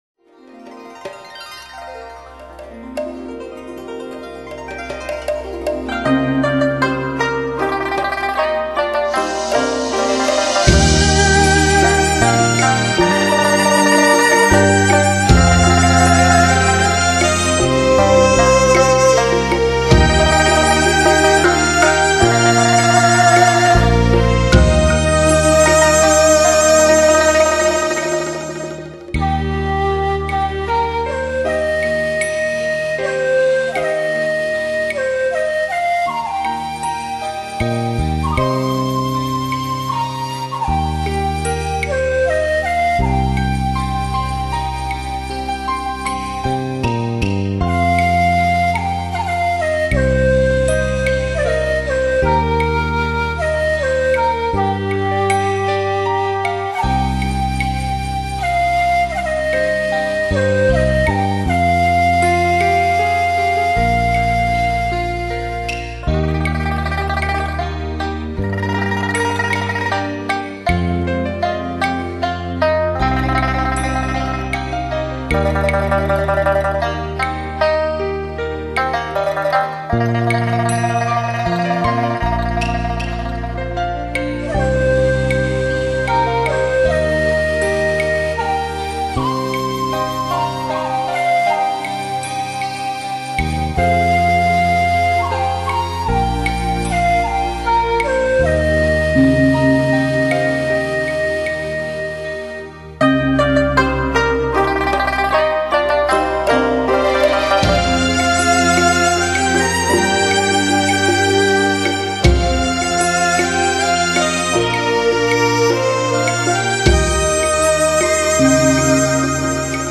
精挑细选的养心音乐，
能让您安神定意，舒展身心，